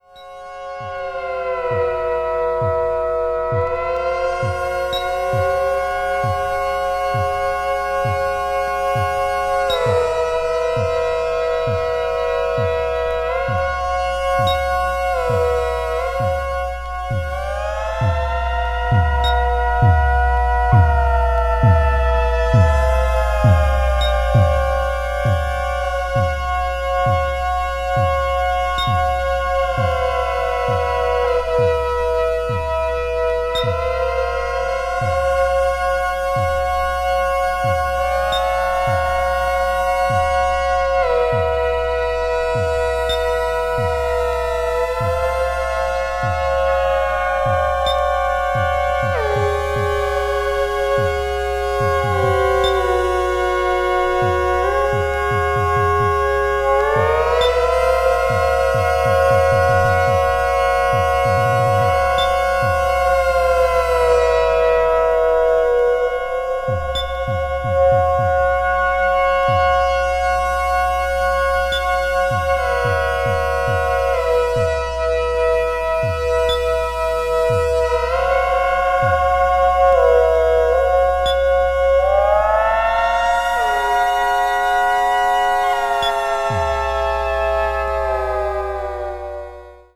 a group consisting of three Japanese synthesizer players